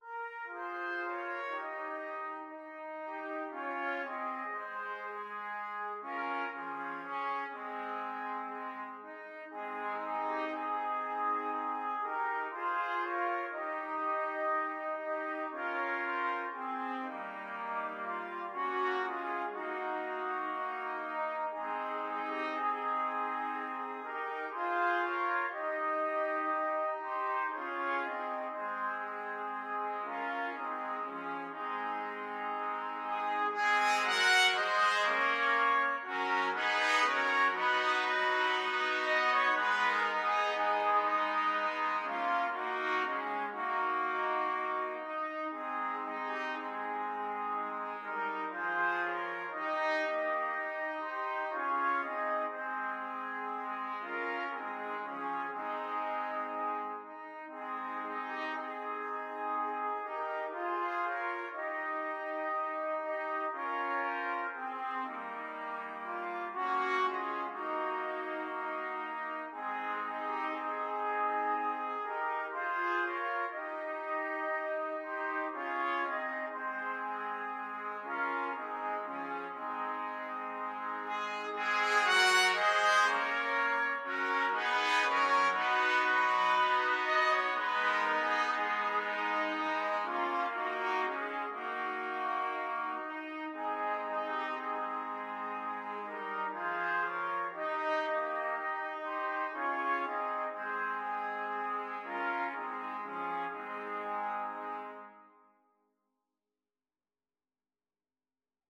3/4 (View more 3/4 Music)
Slow, expressive =c.60
Trumpet Quartet  (View more Easy Trumpet Quartet Music)
Classical (View more Classical Trumpet Quartet Music)